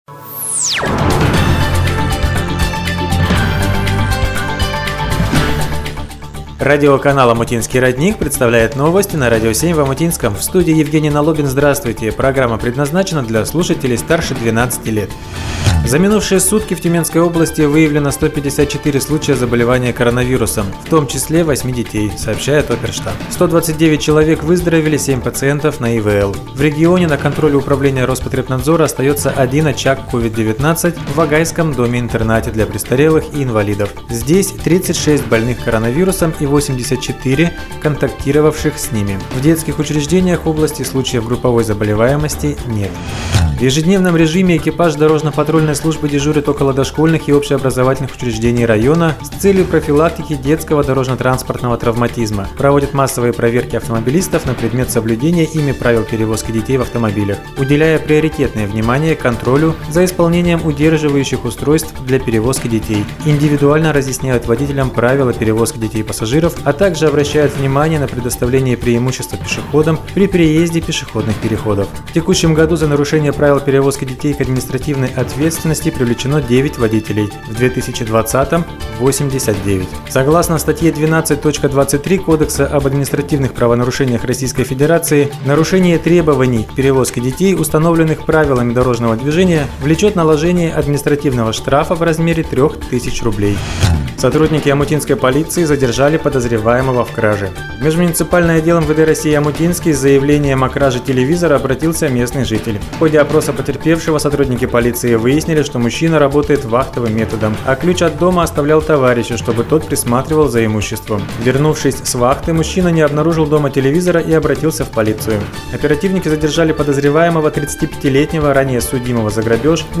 Новости!